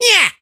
carl_atk_vo_03.ogg